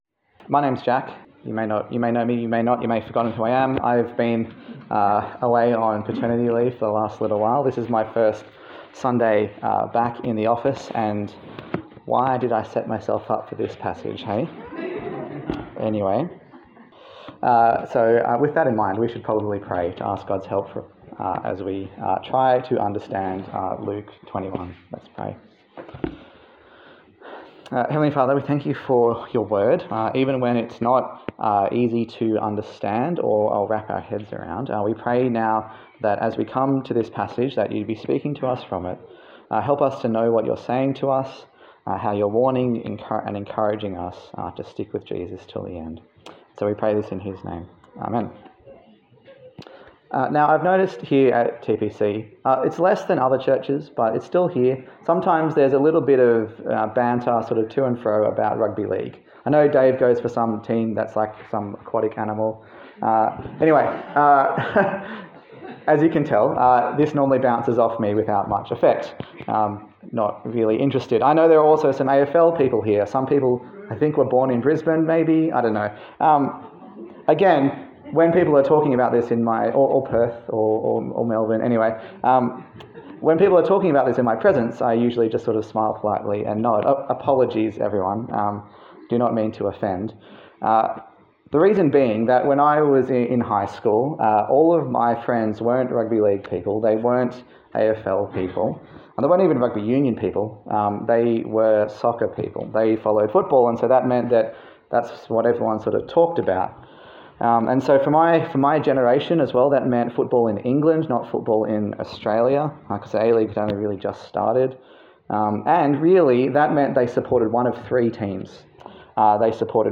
Luke Passage: Luke 21 Service Type: Sunday Service